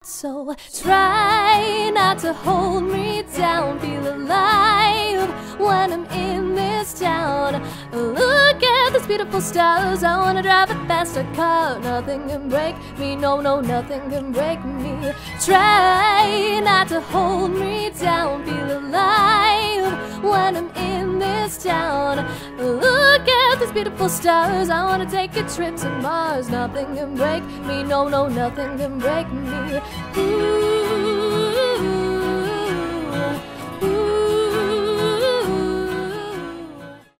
женский голос
поп